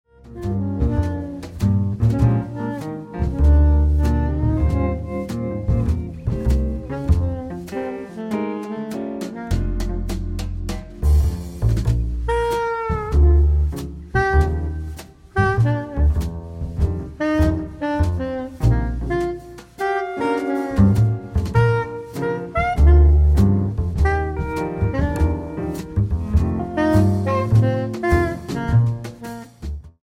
saxophone, bassclarinet
piano
guitar
drums, percussion